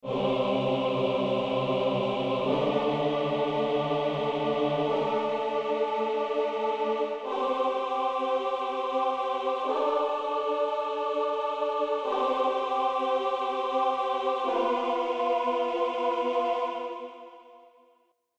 Tritono.mp3